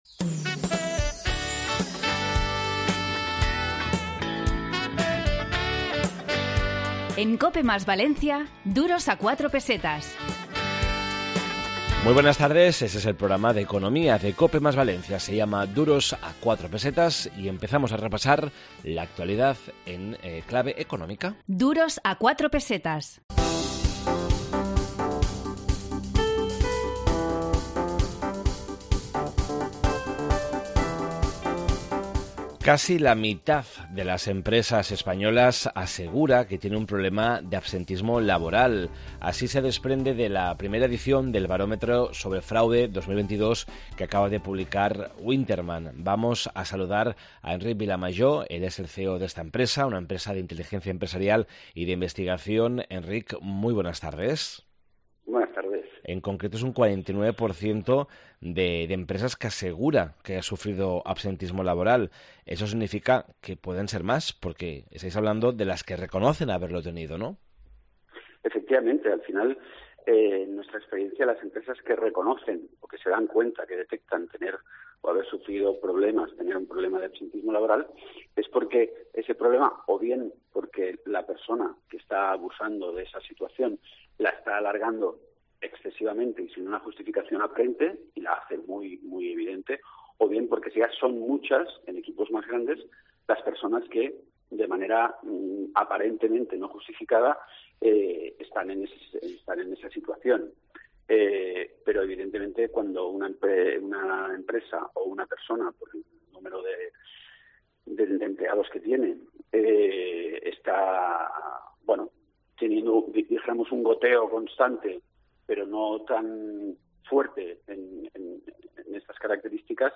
Esta semana en Duros a 4 Pesetas de COPE + Valencia, en el 92.0 de la FM, hemos preparado un programa dedicado a los fraudes en las empresas, la reestructuración empresarial y el derecho concursal, y el Consorcio de Compensación de Seguros.